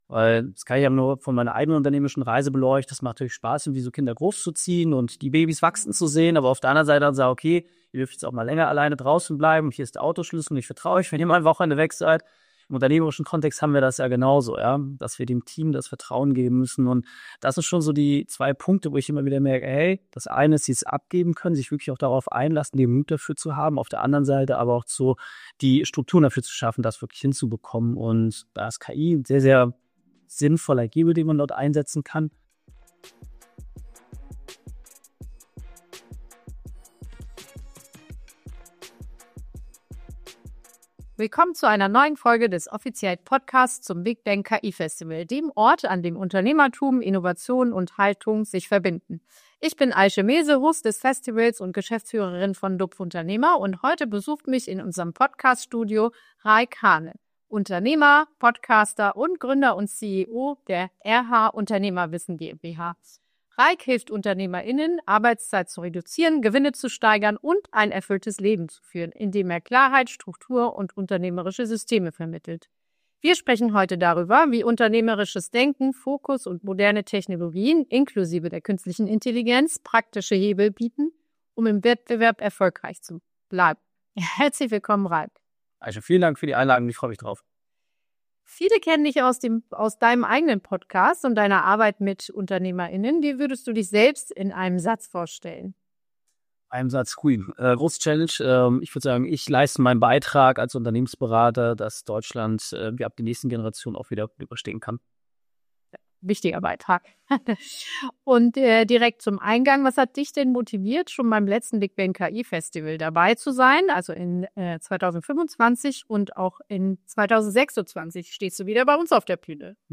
Im Gespräch wird deutlich: Nachhaltiger Unternehmenserfolg entsteht nicht durch mehr Einsatz, sondern durch Klarheit, Fokus und funktionierende Systeme.